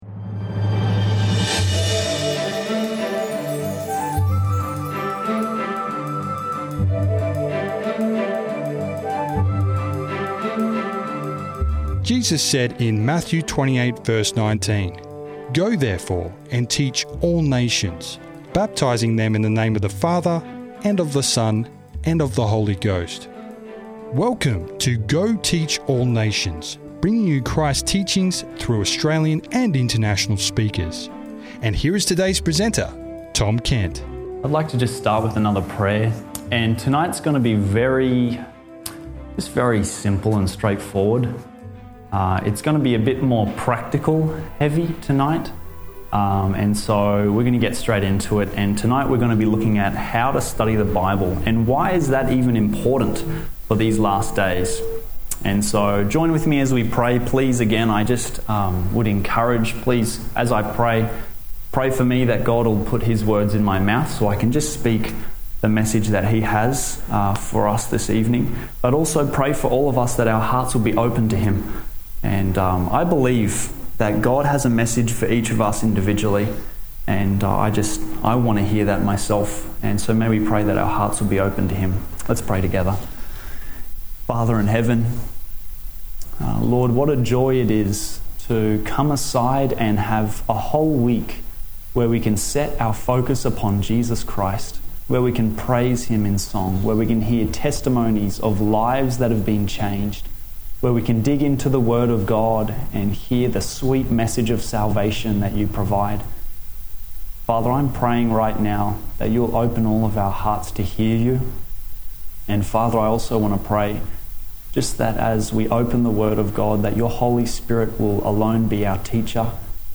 How to Study the Bible With Power – Sermon Audio 2613